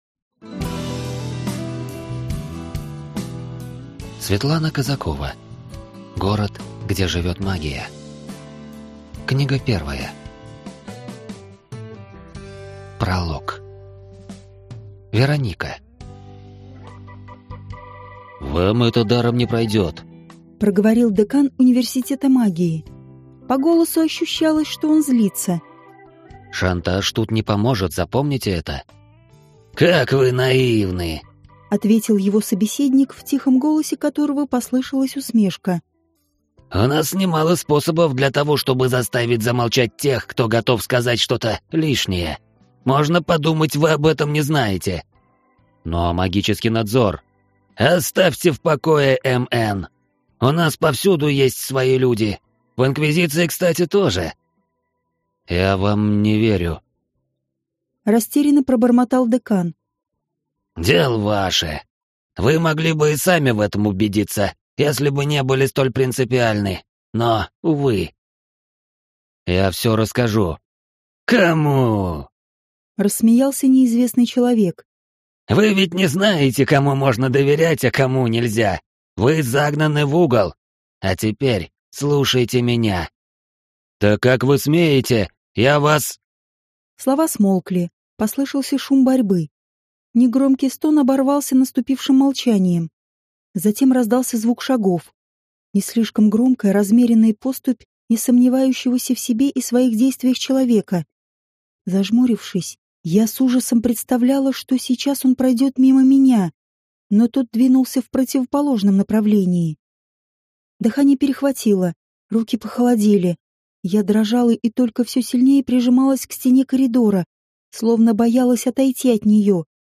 Аудиокнига Город, где живёт магия | Библиотека аудиокниг
Прослушать и бесплатно скачать фрагмент аудиокниги